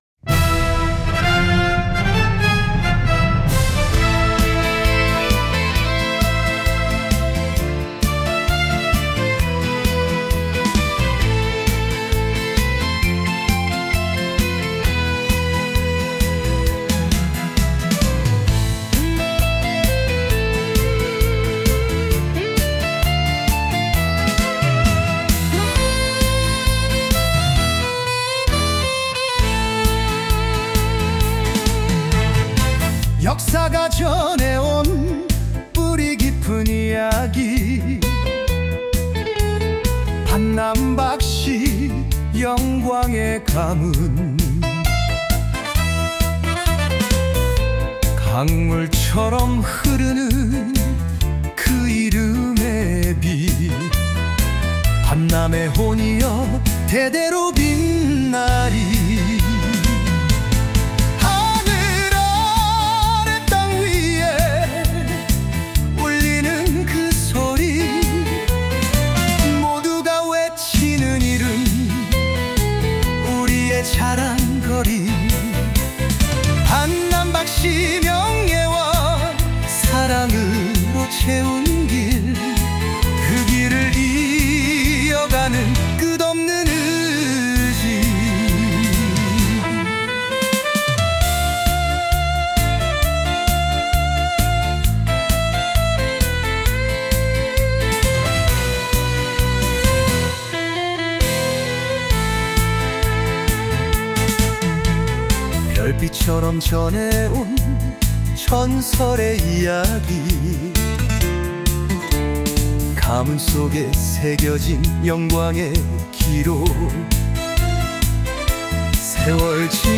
이번에 선정된 반남가는 제례용과 행사용으로 분류 했으며, 아울러 신세대를 위한 힙합과 R&B 장르도 추가하였습니다.